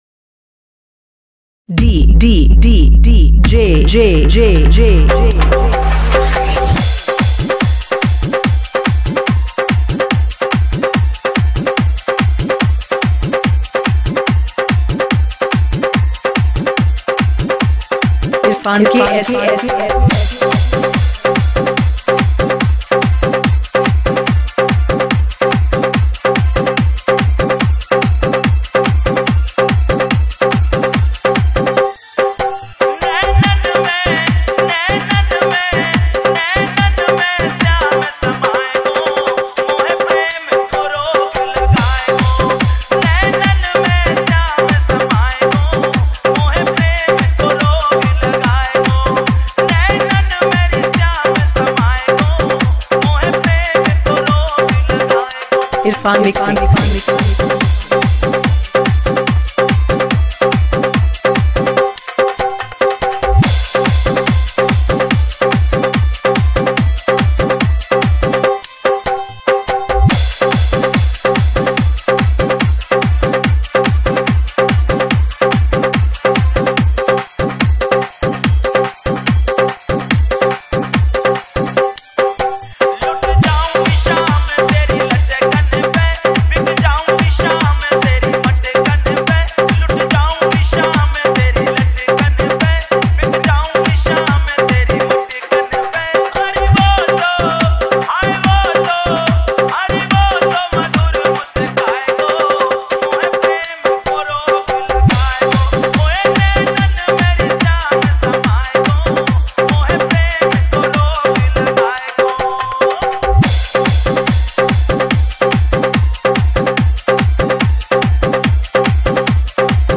full remix DJ